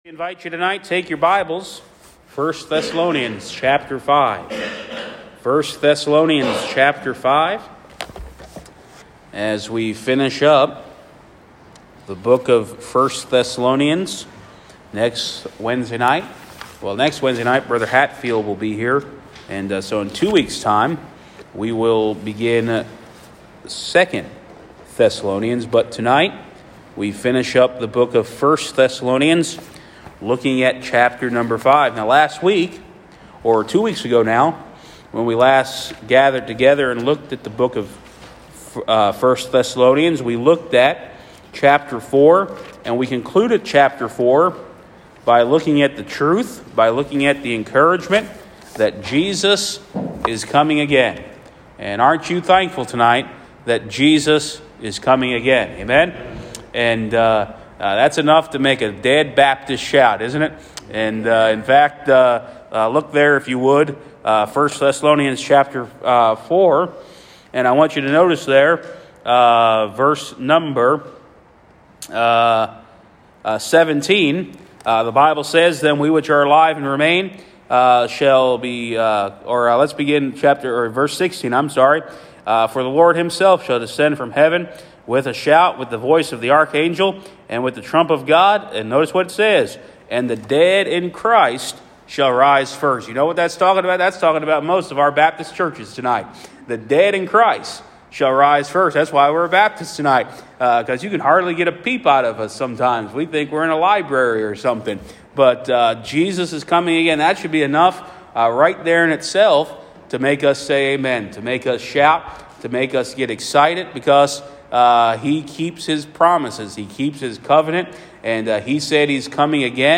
Sermons | First Baptist Church of Sayre, PA
Wednesday Evening Bible Study